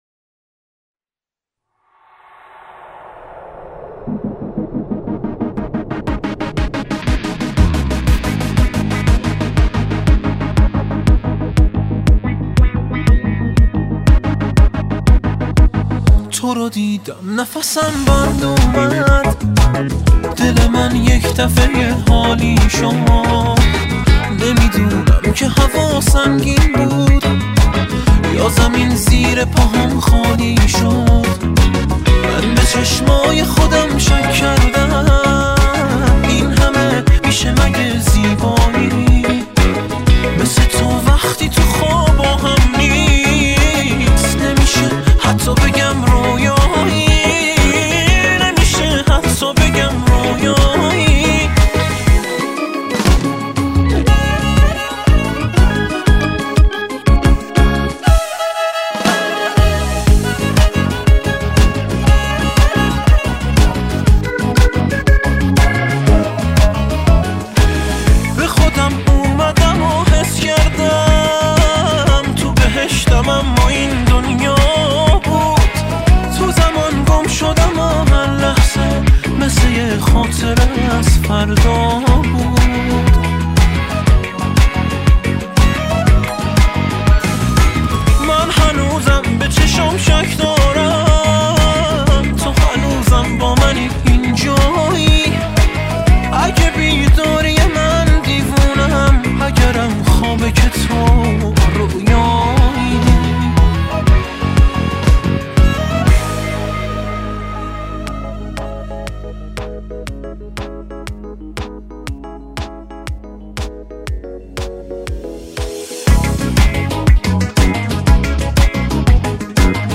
хонандаи эронӣ